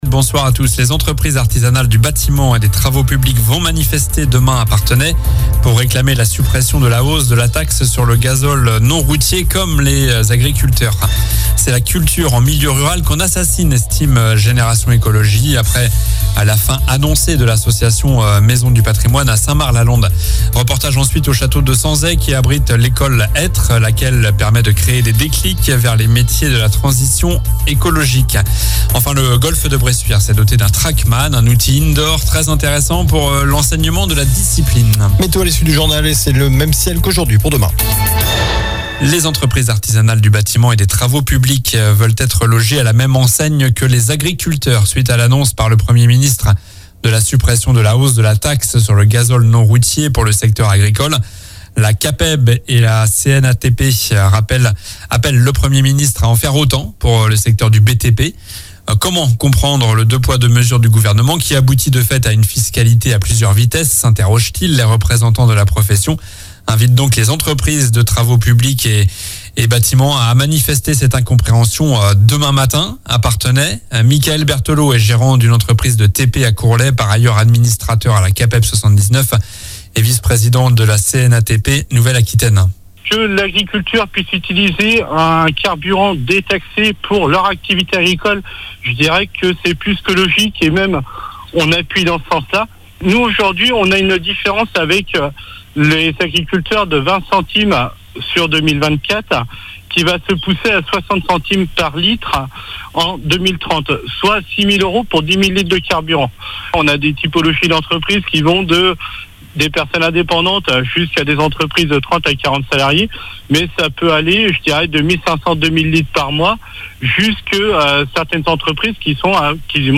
Journal du lundi 05 février (soir)